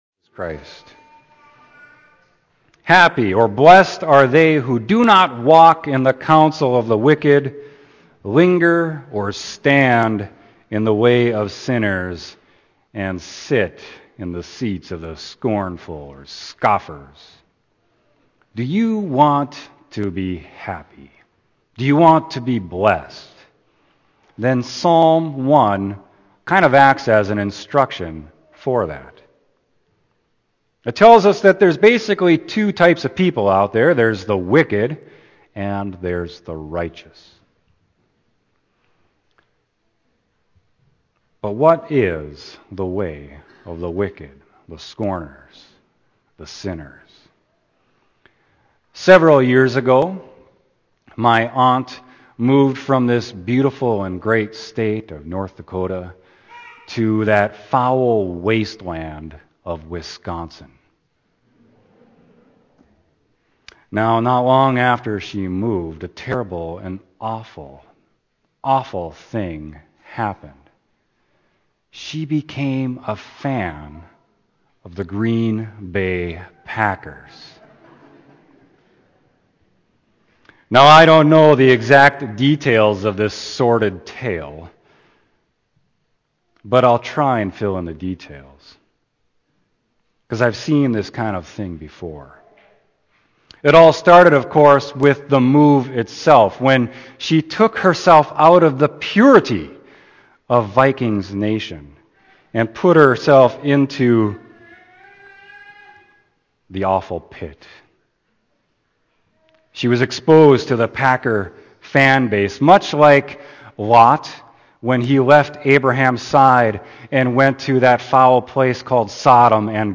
Sermon: Psalm 1